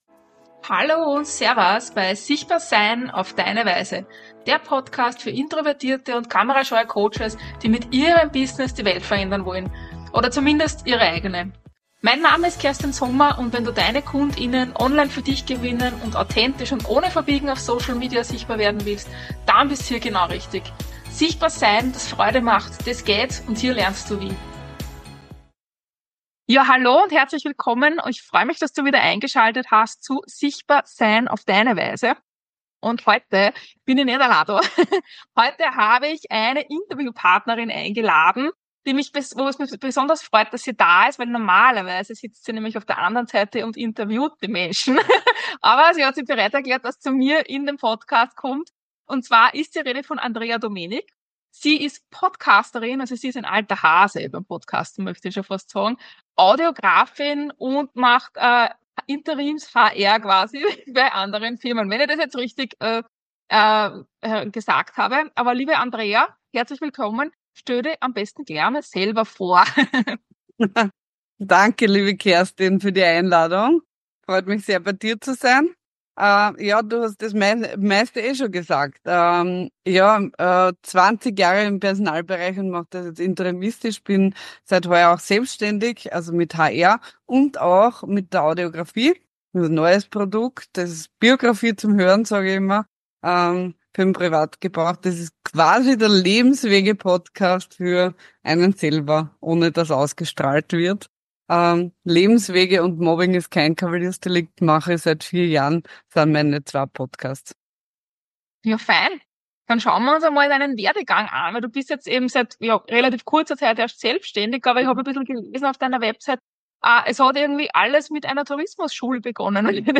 Ich bin heute nicht alleine, sondern darf meine erste Interviewpartnerin im Podcast begrüßen.